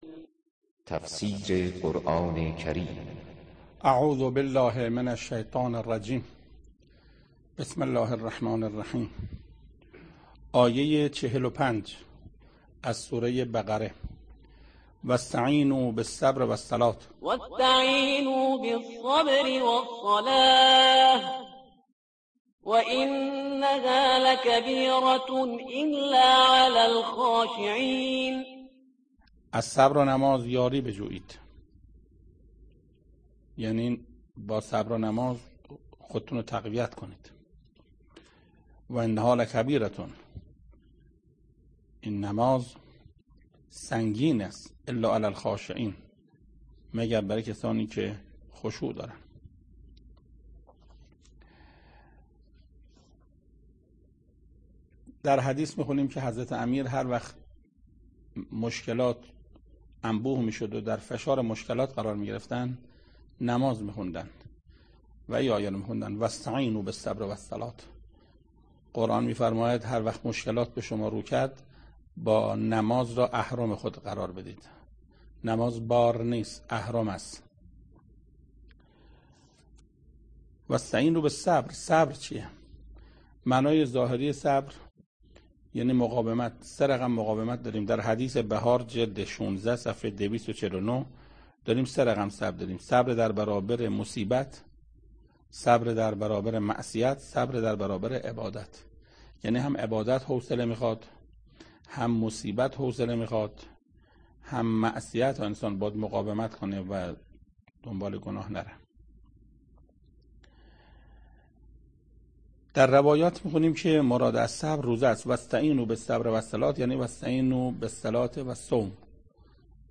تفسیر چهل و پنجمین آیه از سوره مبارکه بقره توسط حجت الاسلام استاد محسن قرائتی به مدت 10 دقیقه